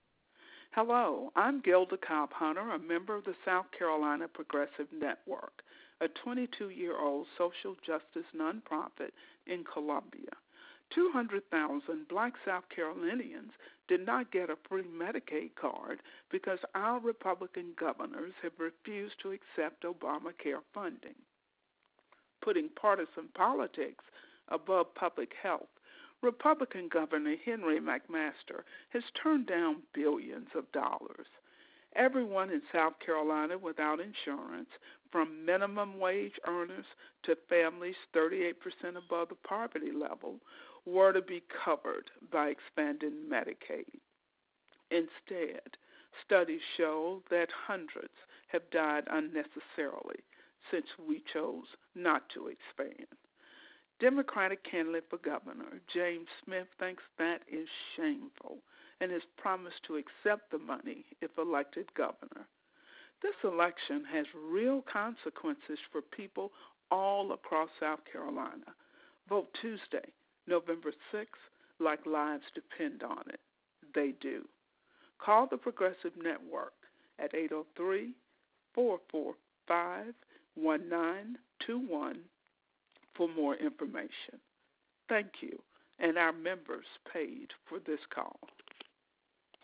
Rep. Gilda Cobb-Hunter, a founding member of the Network, recorded a call that went to our targeted voters.